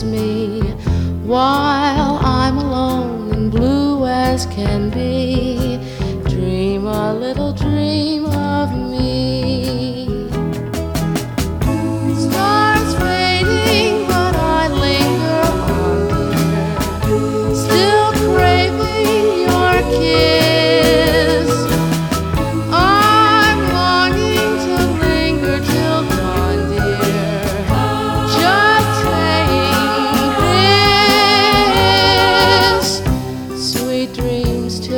Folk-Rock
Жанр: Поп музыка / Рок / Фолк